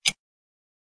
T_Clock.wav